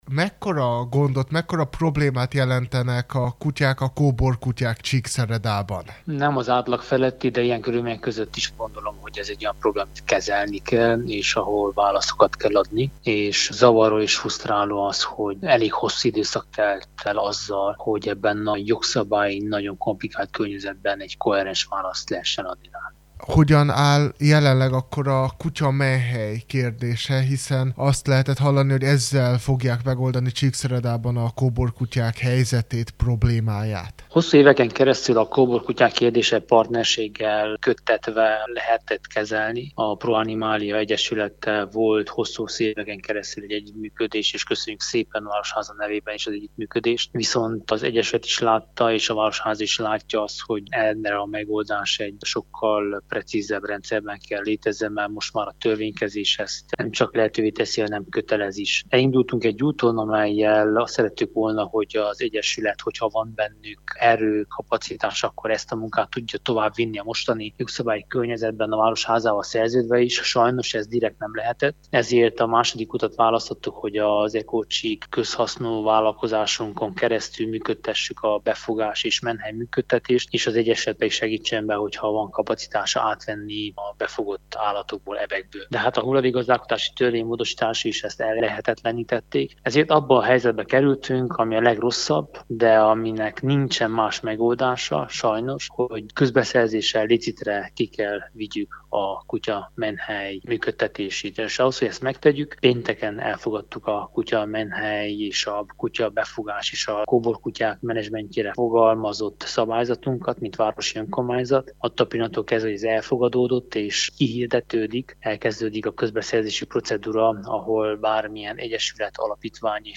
mondta Korodi Attila, Csíkszereda polgármestere